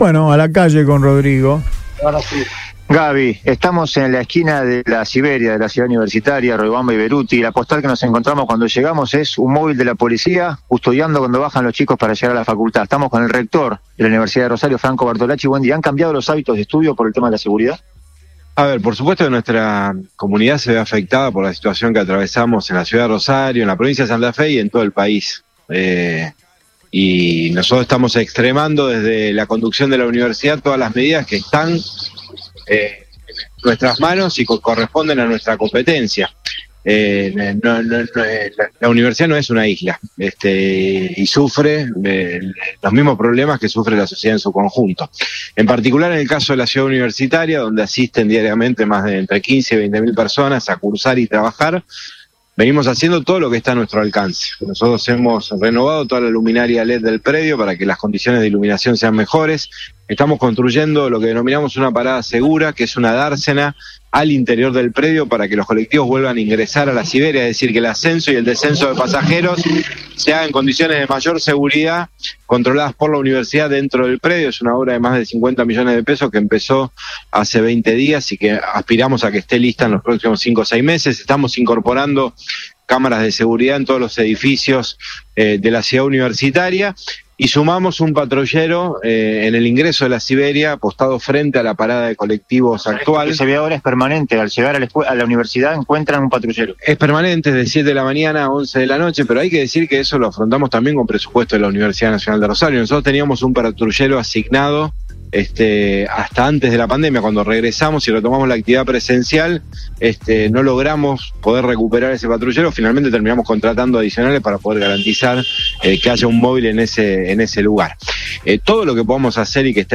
En ese marco, señaló al móvil de Radio Boing que “por supuesto que nuestra comunidad se ve afectada por la situación que atravesamos en la ciudad de Rosario, en Santa Fe y en todo el país”.